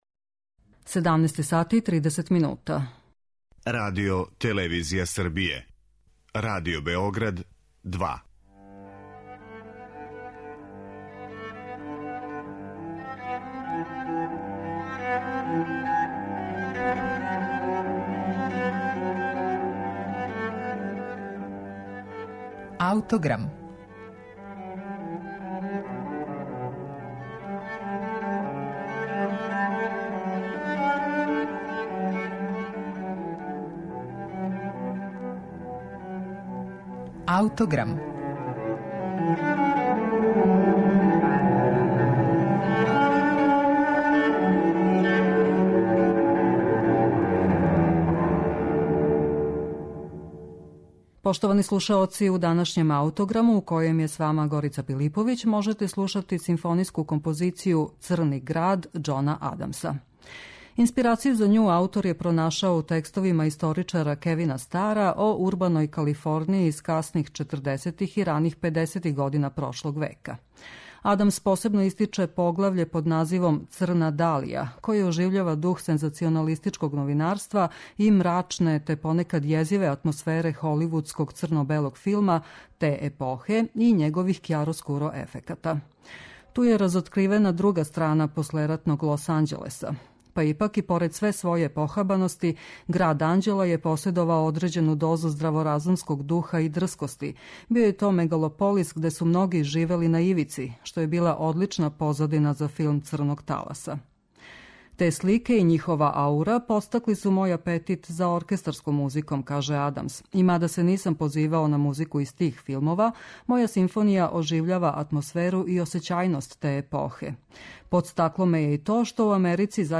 симфонијску композицију